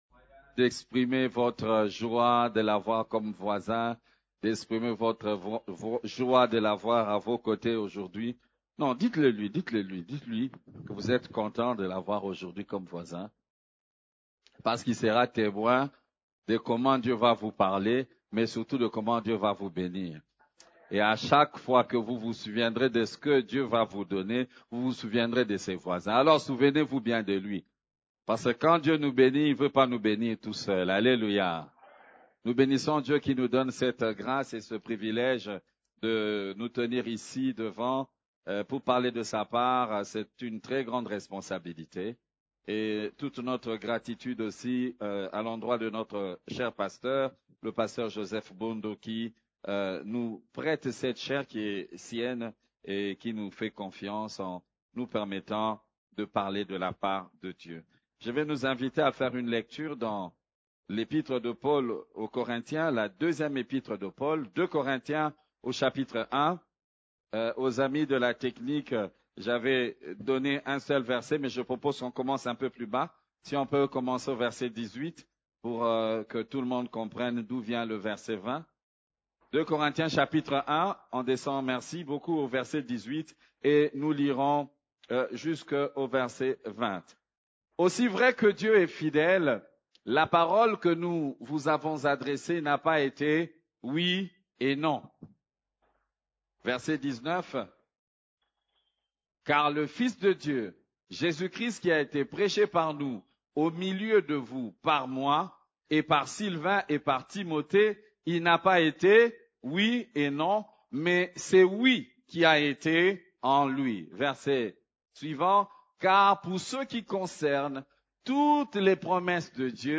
CEF la Borne, Culte du Dimanche, Jésus-Christ , Le Oui et L'Amen